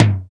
LINN TOM.wav